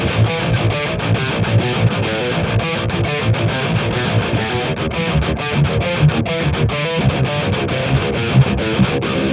Gitarre/Metal/7Saitig
Ich hab zwar nur eine 25.5 Klampfe auf B Standard mal eben auf Drop A gestimmt. Daher eiert die tiefe Saite schon ganz schön.
Habs nur mal eben quick n dirty versucht, keine Ahnung ob die Töne alle stimmen. Habe auch nichts geschnitten.